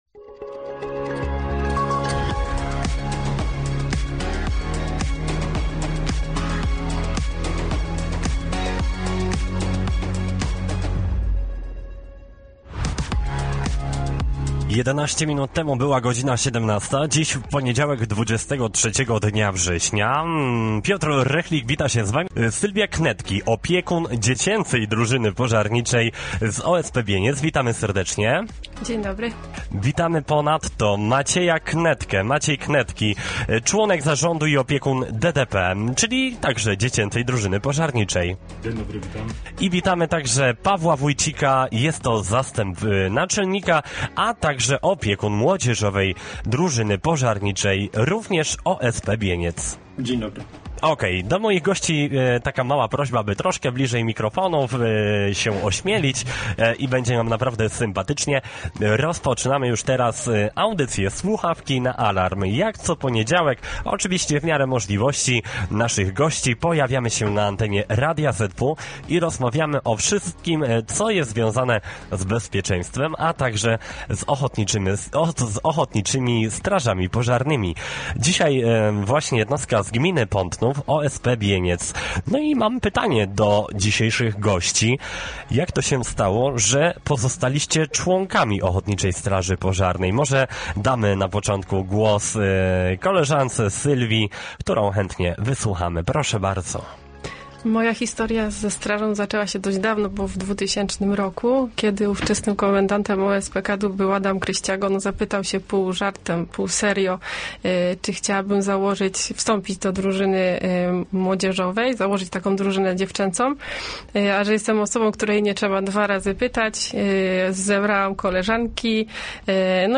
Program „Słuchawki na alarm” emitowany jest na naszej antenie w każdy poniedziałek po godzinie 17:00.